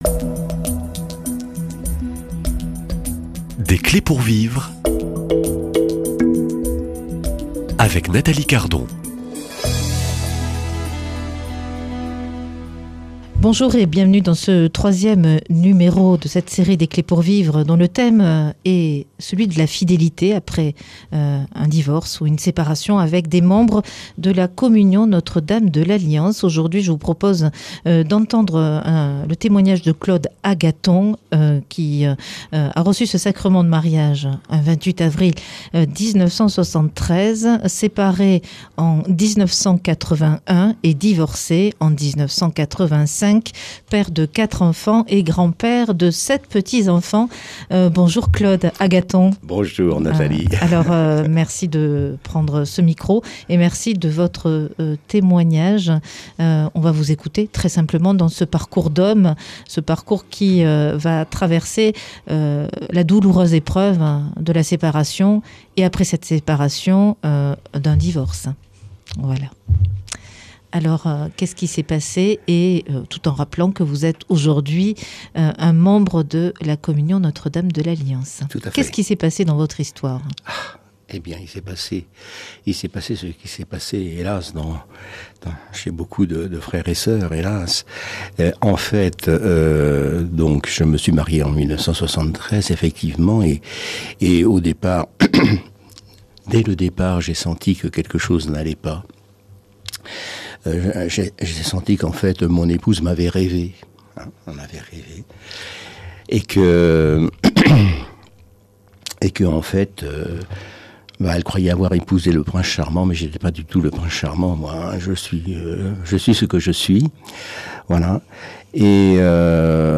Invité témoin